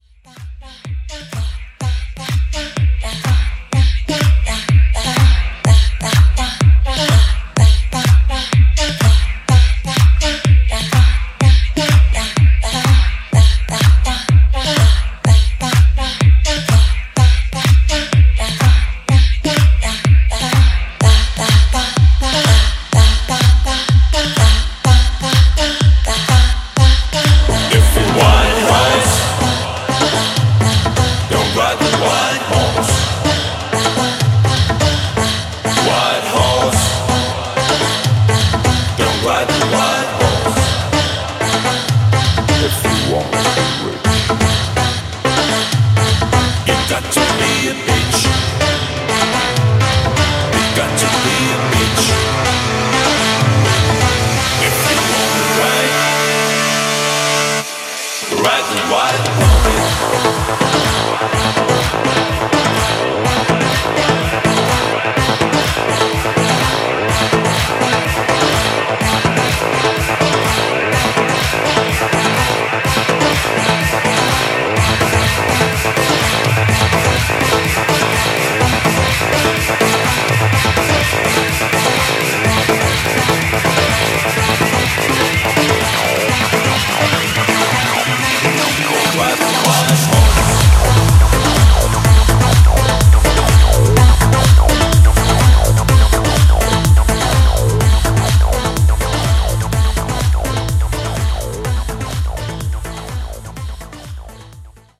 Genres: BOOTLEG
TOP40 Version: Clean BPM: 128 Time